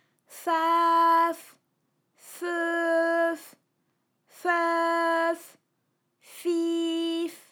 ALYS-DB-001-FRA - First, previously private, UTAU French vocal library of ALYS
fafefeufif.wav